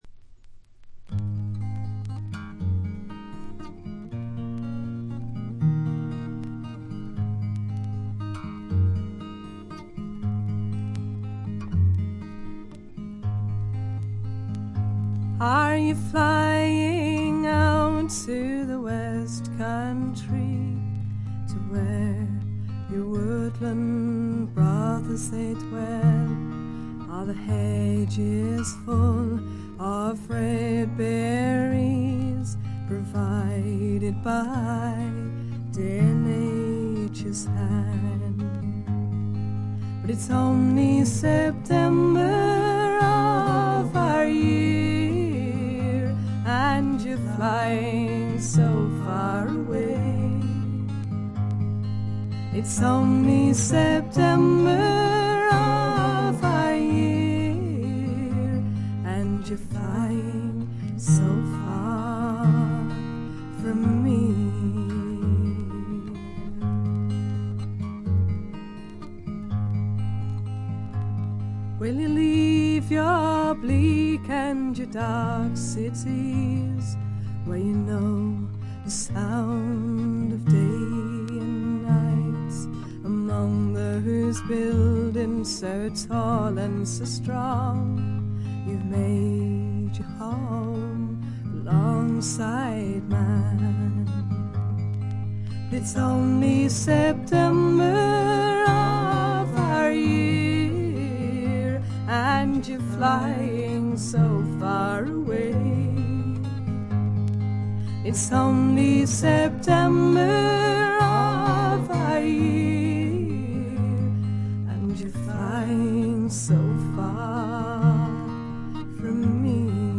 試聴曲は現品からの取り込み音源です。
Vocals, Acoustic Guitar